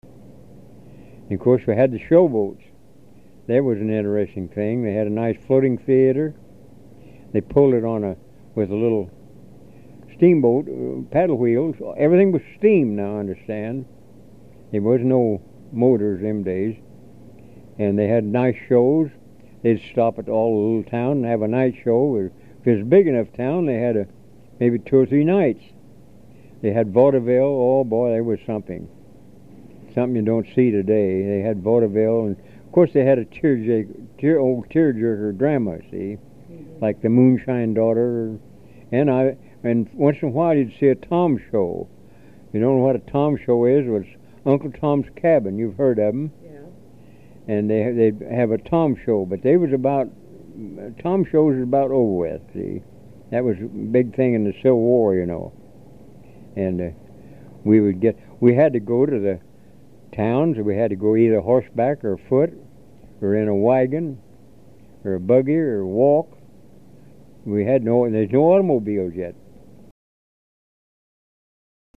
HTR Oral History, 07/17/1